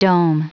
Prononciation du mot dome en anglais (fichier audio)